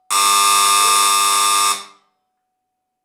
Llamada de un timbre de una casa (zumbido)
zumbido
timbre
Sonidos: Hogar